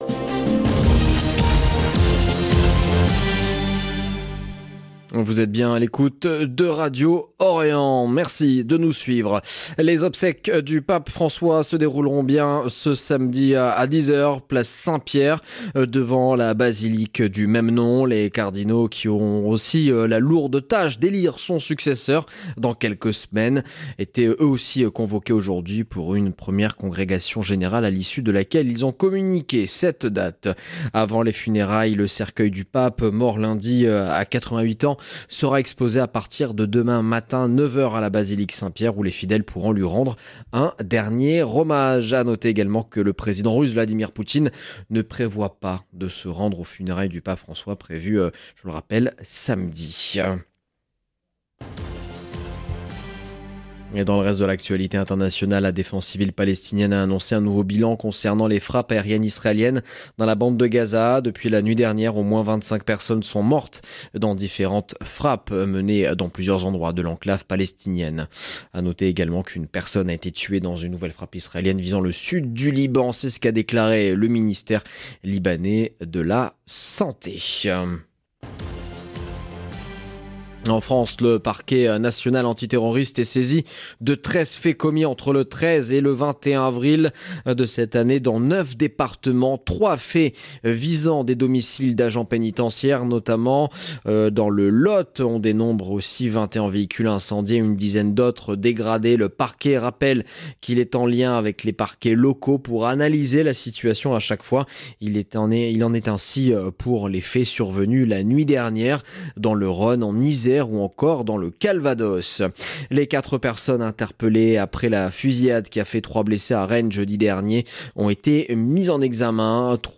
LE JOURNAL EN LANGUE FRANÇAISE DU SOIR 22/04/2025